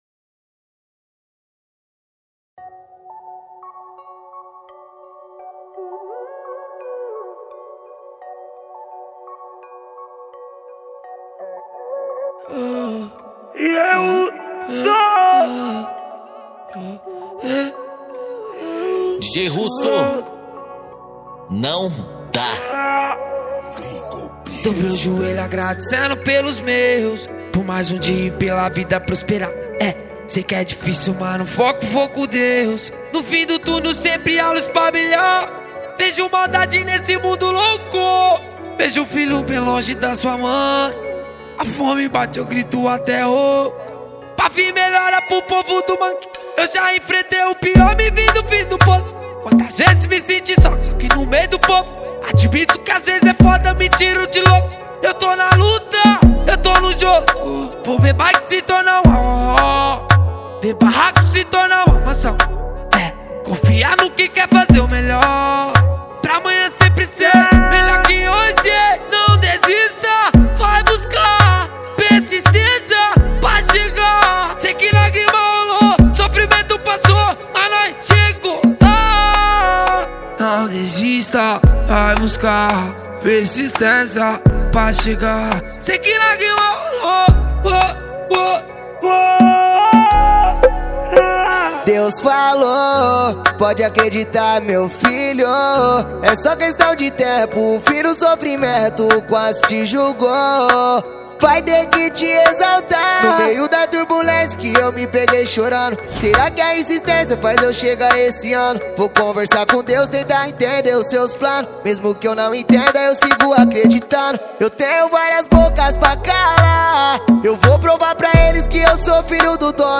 2024-12-28 03:37:15 Gênero: Funk Views